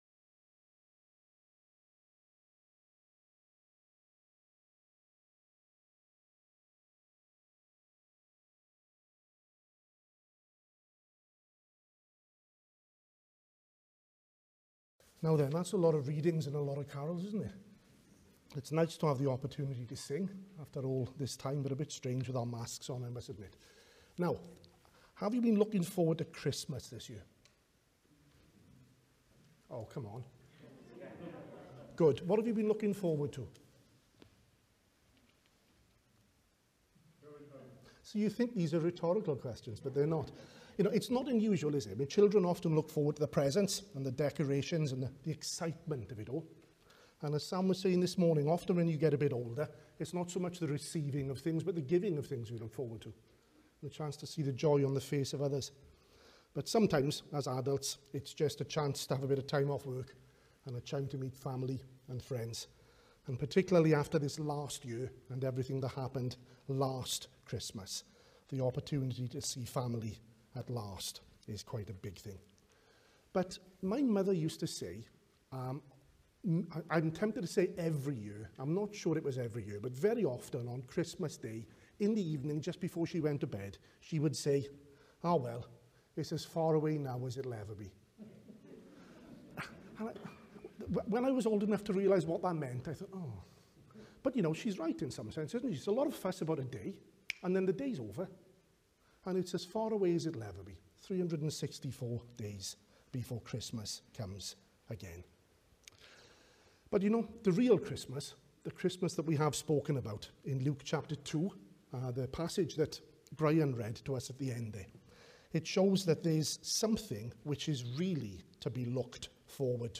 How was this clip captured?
at the evening Carol service